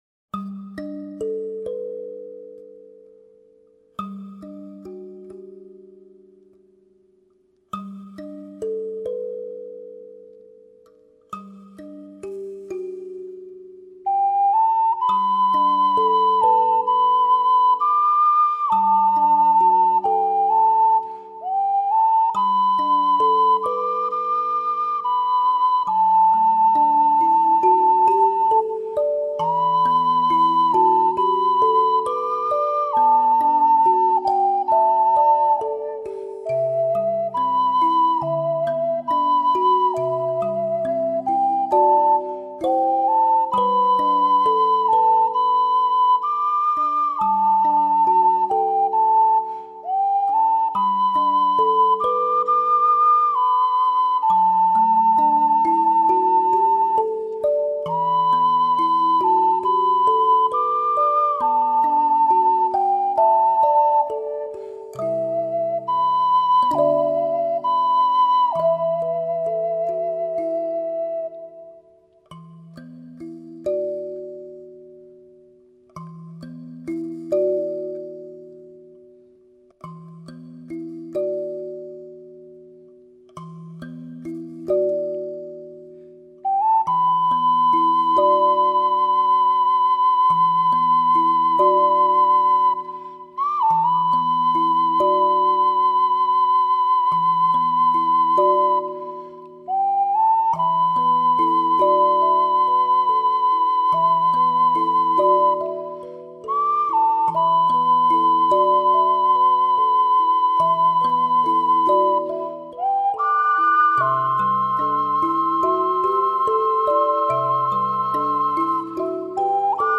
高透度的山林空間 . 高含氧的自然清音樂
.結合日本出林實地收錄的 3D自然音效
.結合真實大自然聲響，體驗自然萬物的和諧之美
.融入取於自然界的高周波聲響